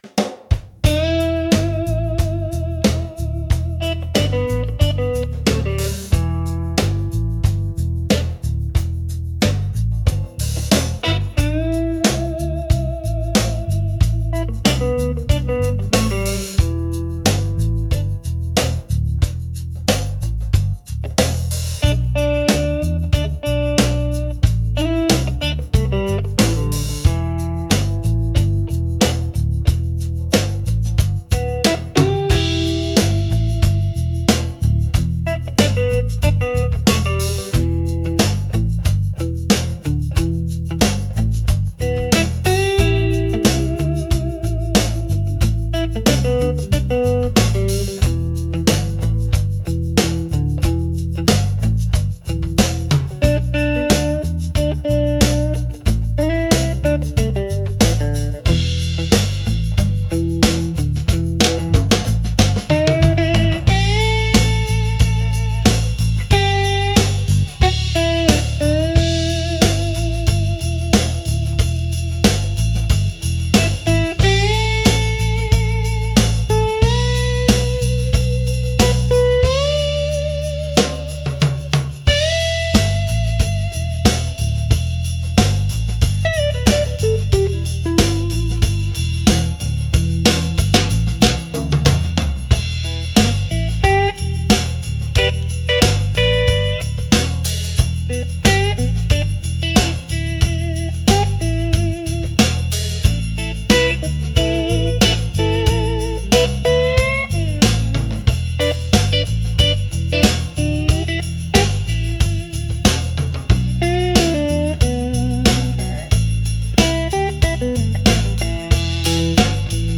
Instrumental Cypress Roots -4.37